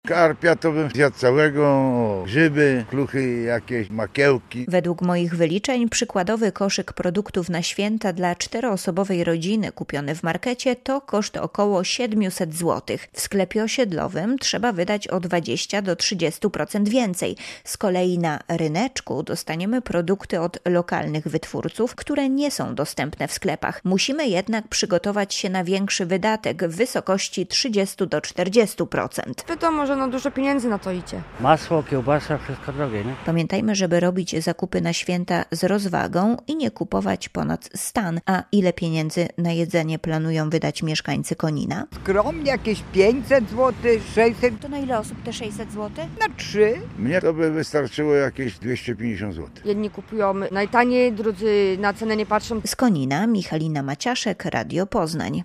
- mówili mieszkańcy.